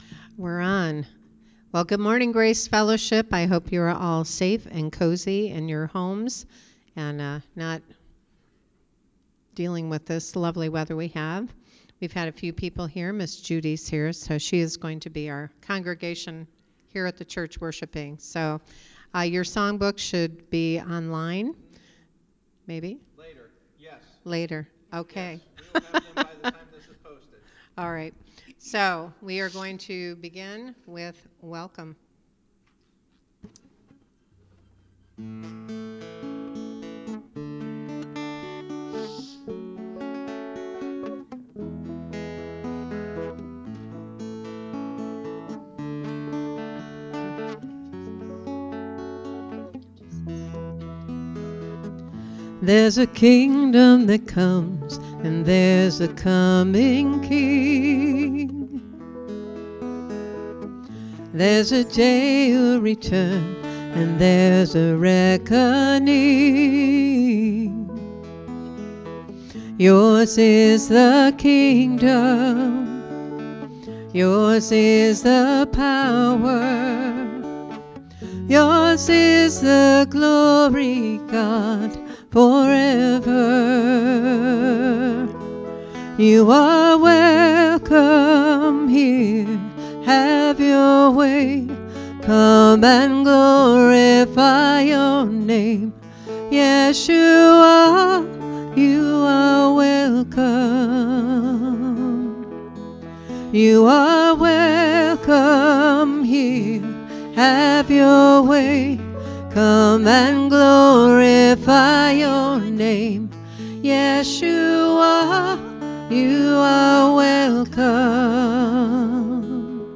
January 23, 2022 Worship Service | A People For God
Due to the weather conditions this week, we were able to only have our worship service without a sermon.